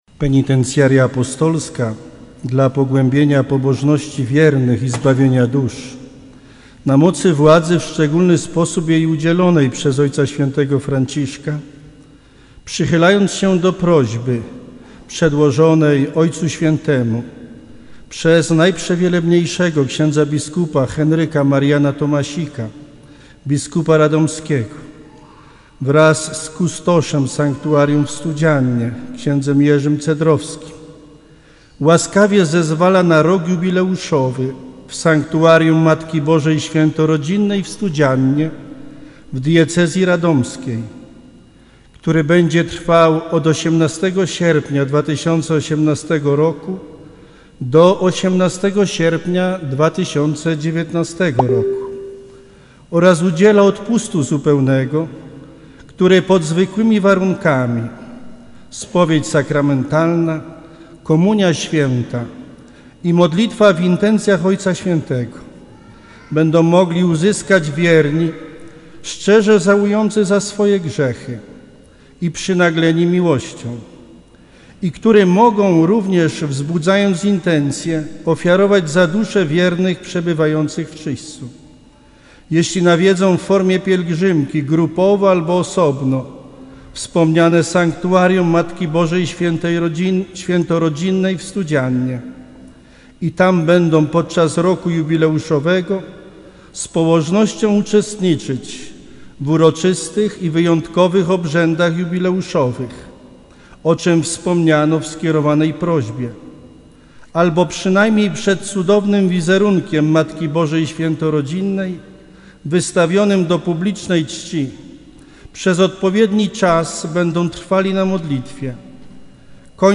Uroczystości jubileuszowe w sanktuarium MB Świętorodzinnej w Studziannie rozłożone zostały na trzy dni.
Ogłosiła to watykańska Penitencjaria Apostolska, a stosowny dekret odczytał ks. prał. Krzysztof Nykiel, regens Penitencjarii.
ks. prał. Krzysztof Nykiel, Dekret o odpuście: